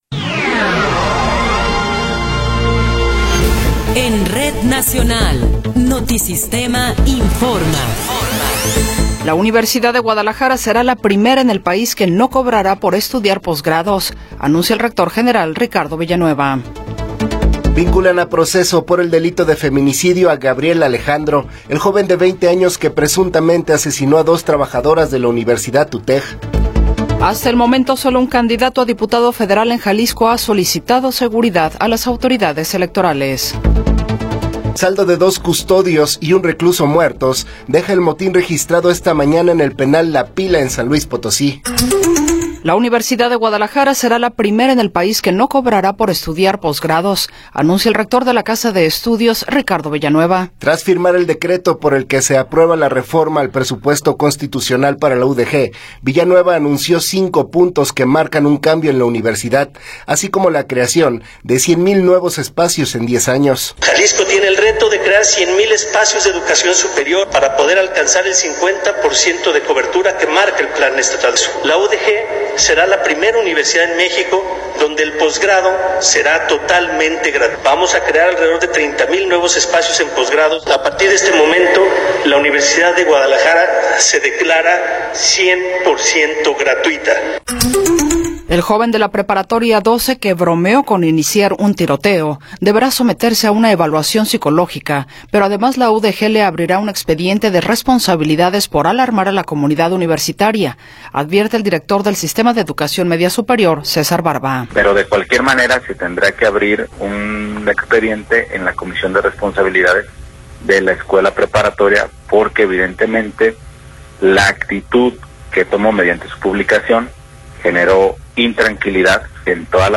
Noticiero 14 hrs. – 14 de Marzo de 2024
Resumen informativo Notisistema, la mejor y más completa información cada hora en la hora.